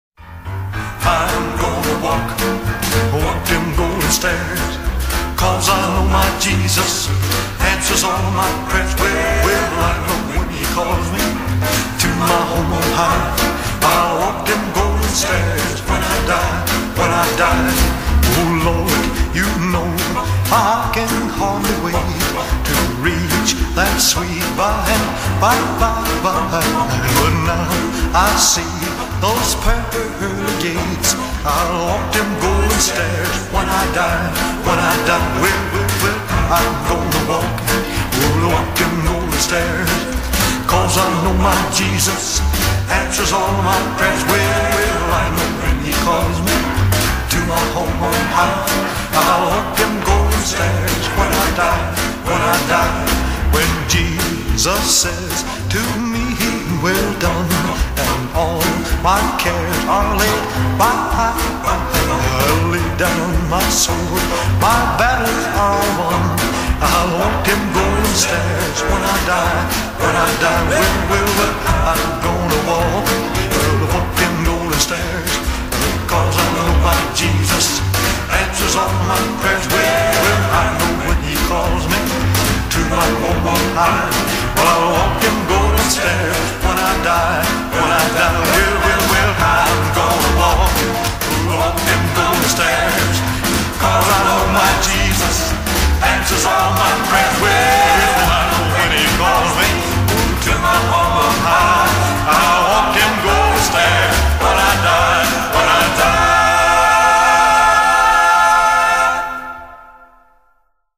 Following are the professional, recorded versions.